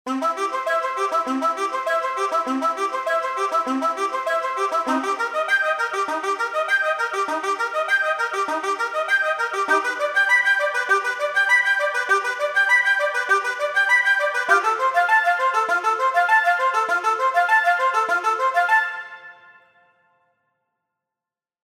Während man beim Staccato-Saxofon unter anderem auf Double-Tongue-Spielweisen trift …
… bieten die Staccato-Flöten Overtone-Artikulationen:
Beide eignen sich für einen gemeinsamen Auftritt, etwa mit einem Arpeggio: